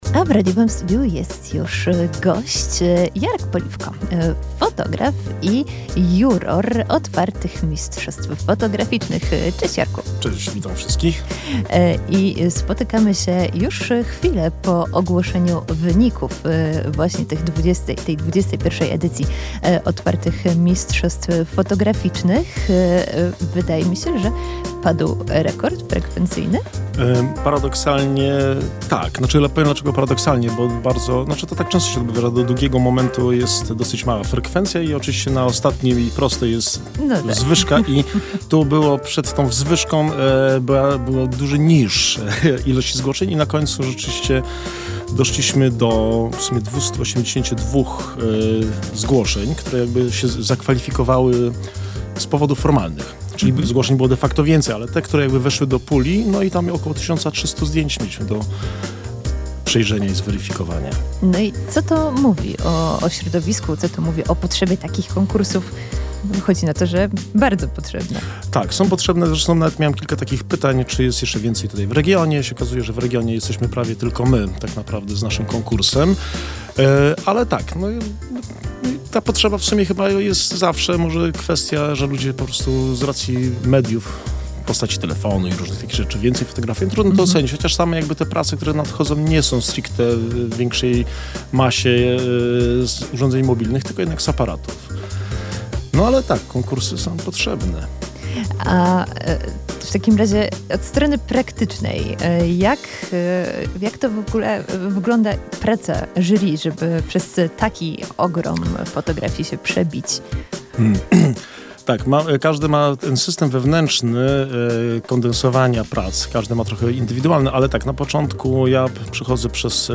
opowiedział w studiu Radia UWM FM